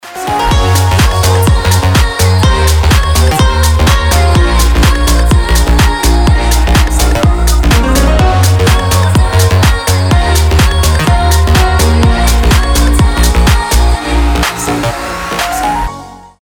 громкие
женский голос
пианино
house
Классный рингтон со звонкой игрой на клавишах